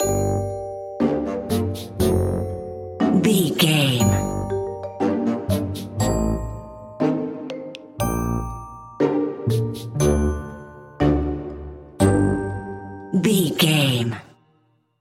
Aeolian/Minor
pizzicato
strings
glockenspiel
marimba
mellow
playful